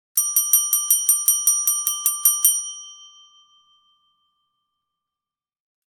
Hotel Service Bell Repeated Ringing Sound Effect
Description: Hotel service bell repeated ringing sound effect. A person nervously rings a desk bell, repeating the chime several times. This hotel service bell sound effect captures the short, clear rings perfectly.
Hotel-service-bell-repeated-ringing-sound-effect.mp3